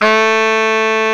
SAX B.SAX 19.wav